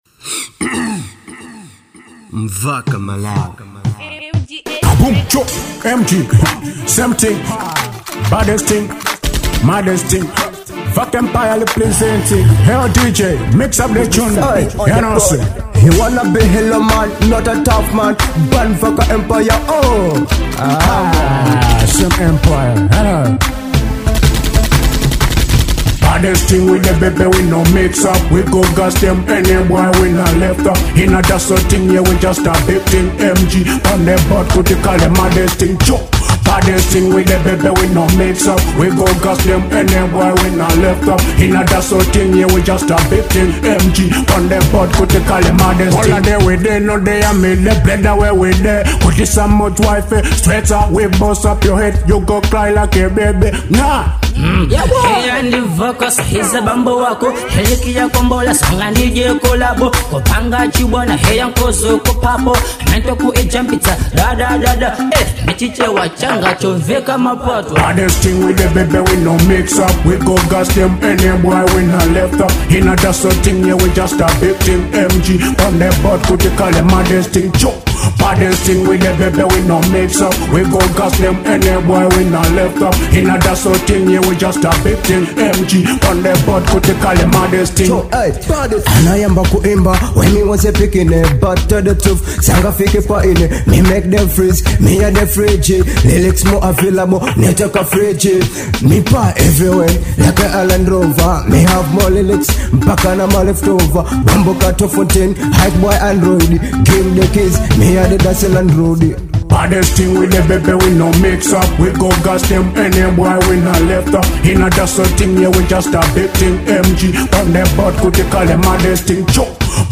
type: Dancehall